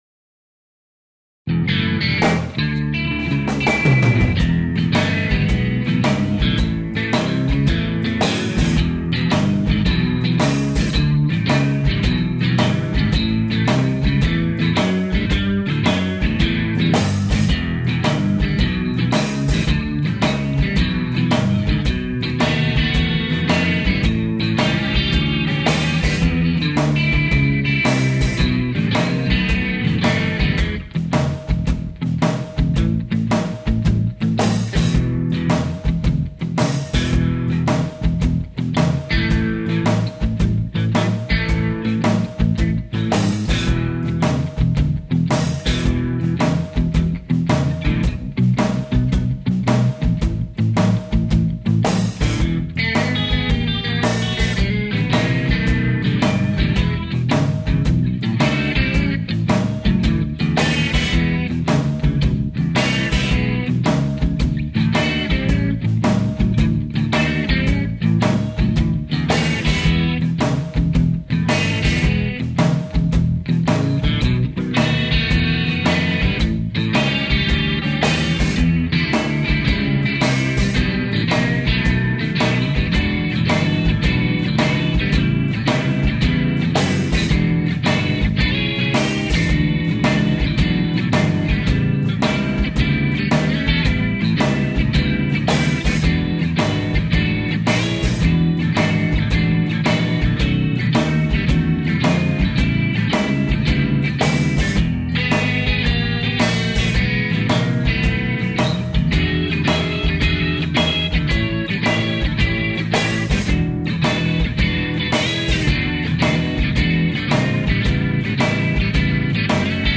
bluesbackingtrack_langeversie.mp3